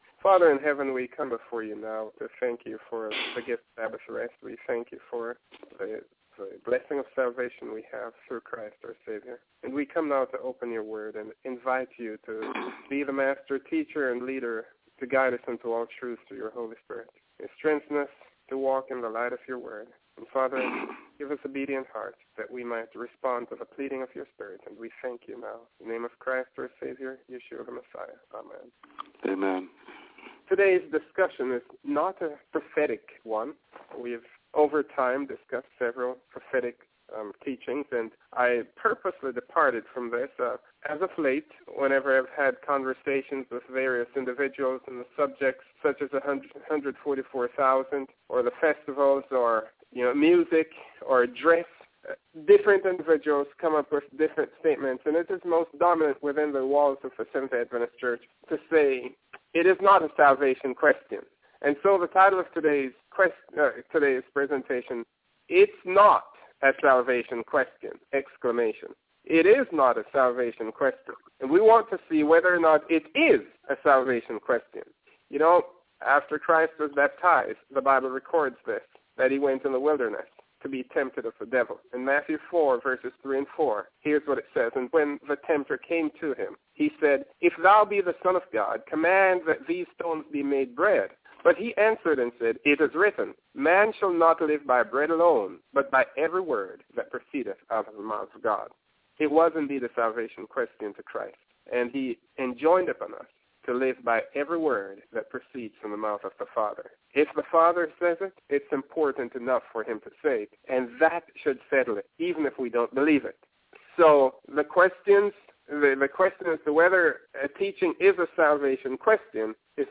Most Christians today do not believe that our diet, dress, music and social conduct constitute a matter of salvation. This sermon presents the truth that it was a violation of dietary restrictions which has resulted in the human race’s 6,000 year rendezvous with sin, and only obedience to every divine precept will be accepted before our heavenly King, the blood of Y’shuah notwithstanding.